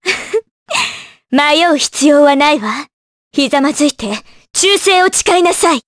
Artemia-vox-get_jp.wav